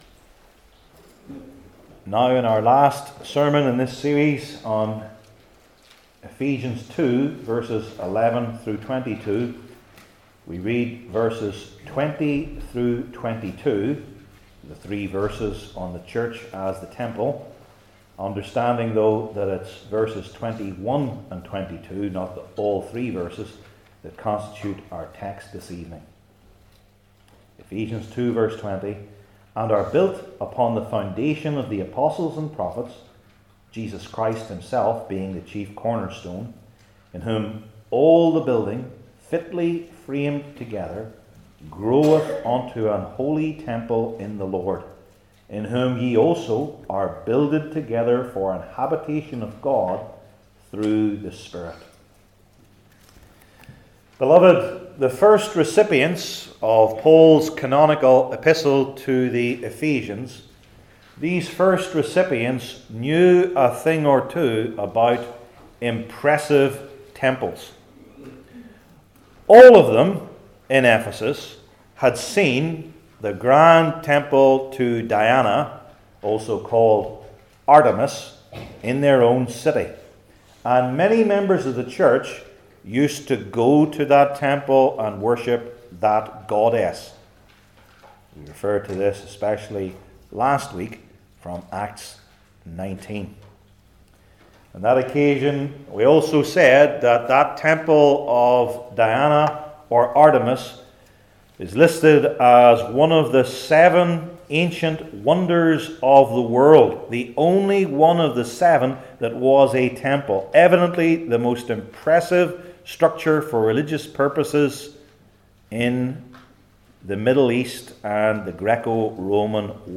Ephesians 2:21-22 Service Type: New Testament Sermon Series I. Its Harmonious Fitting II.